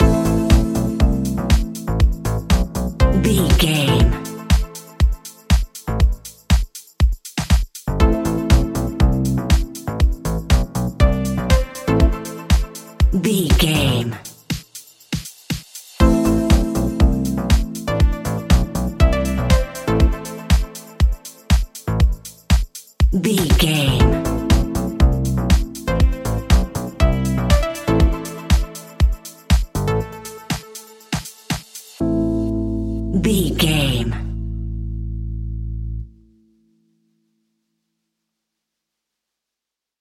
Aeolian/Minor
groovy
uplifting
driving
energetic
drums
bass guitar
synthesiser
electric piano
funky house
deep house
nu disco
upbeat
instrumentals